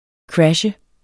Udtale [ ˈkɹaɕə ]